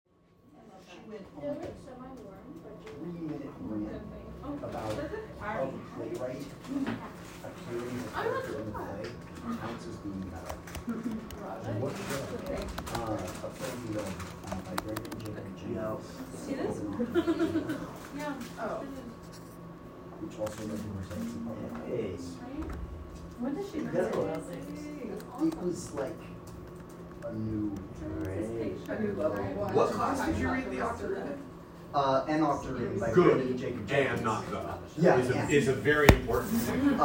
Field Recording #3
The sounds heard in this clip consists of background chatter that gradually gets louder, backpacks/chairs/papers moving around, occasional laughter, typing on a computer, and the closing of a computer as the clip ends.
Sounds closest: Air conditioner, music